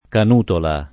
[ kan 2 tola ]